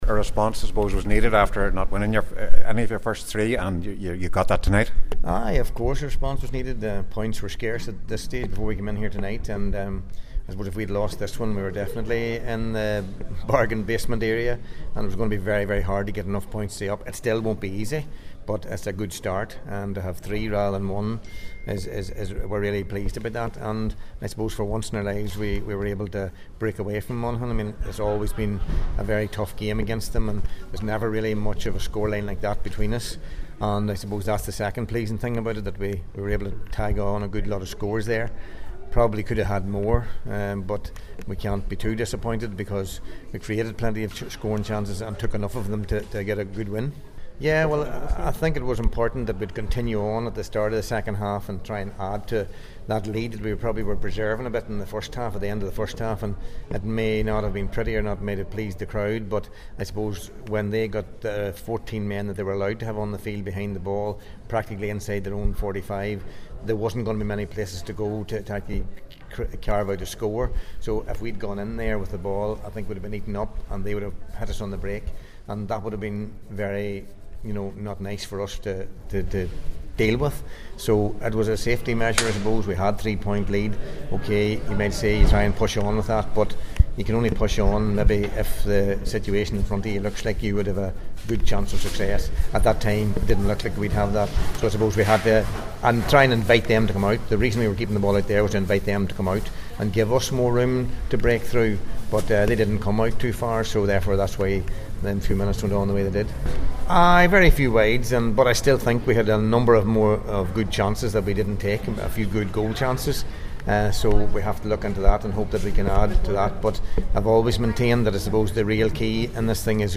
After the match, Tyrone manager Mickey Harte spoke